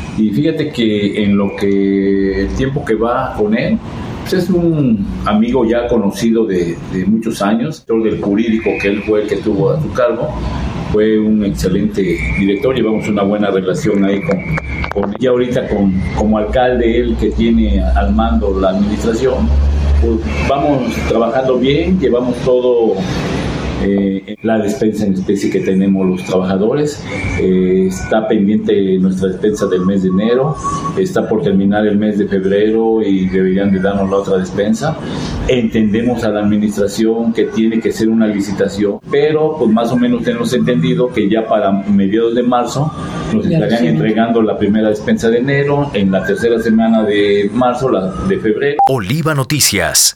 En entrevista destacó los esfuerzos del sindicato en la defensa de los derechos laborales y la obtención de beneficios para sus agremiados.